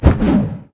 attack2_2.wav